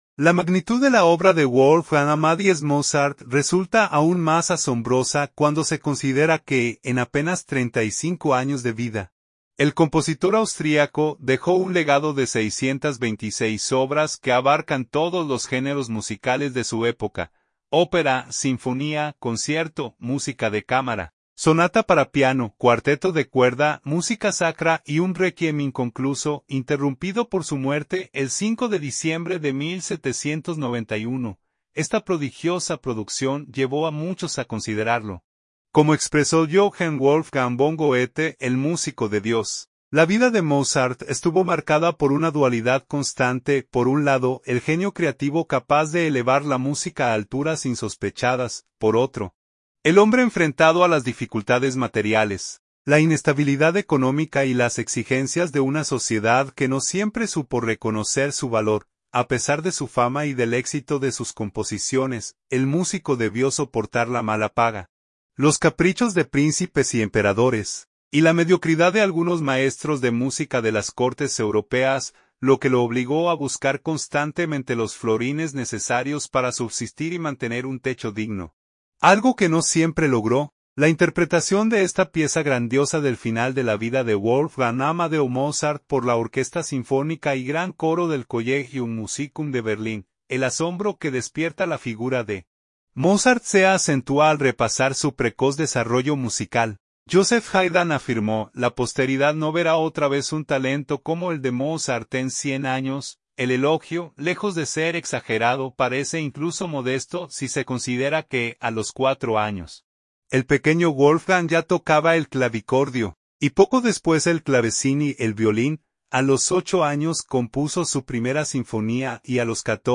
La interpretación de esta pieza grandiosa del final de la vida de Wolfgang Amadeo Mozart por la Orquesta Sinfónica y Gran Coro del Collegium Musicum de Berlín